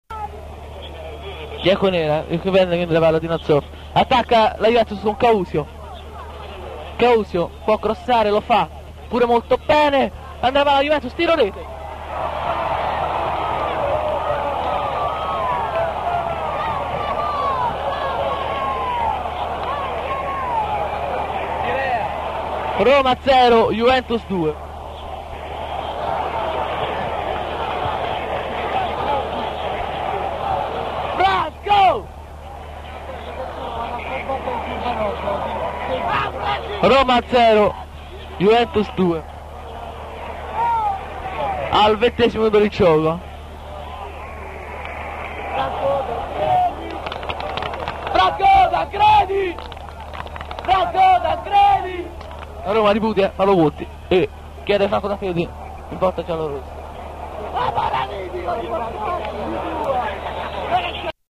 "Ti invio alcuni pezzi della cassetta che ho registrato il 13/04/1980 all'Olimpico durante Roma-Juventus 1-3.
Nel 4°,poi, raddoppia con Scirea  al 20° e scatta la rabbia dei tifosi contro Paolo Conti, e si inneggia alla riserva Tancredi,